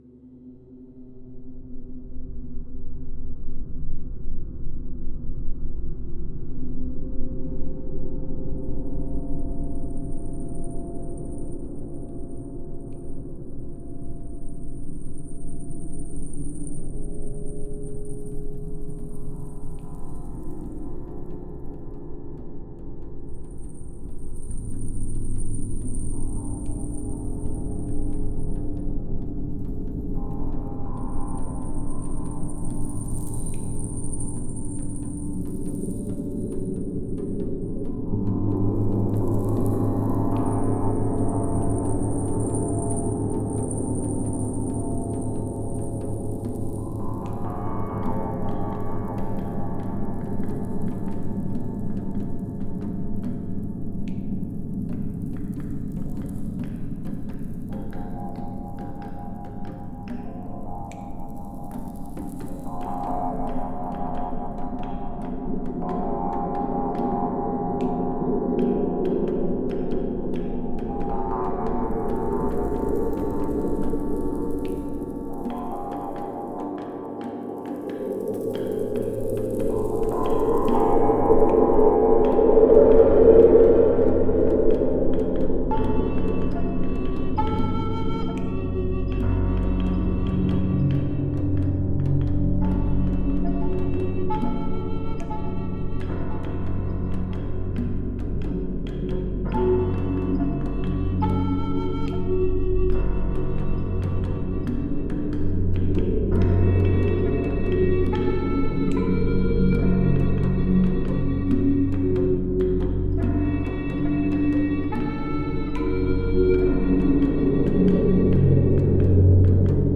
instrumental song
keyboard, flute, and percussion Listen to a Sample! https